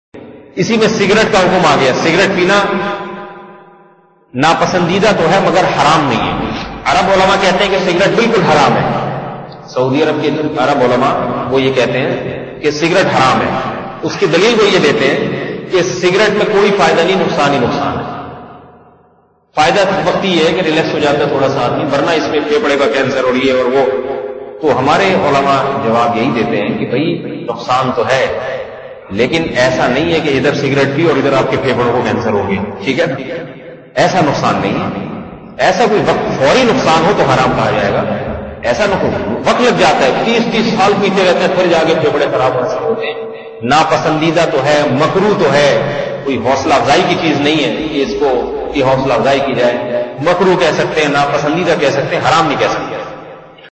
Audio Bayanat